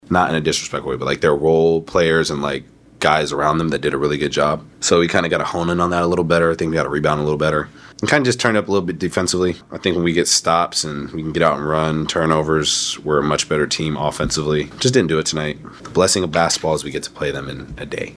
Thunder guard Jalen Williams says Oklahoma City’s defense needs to step up.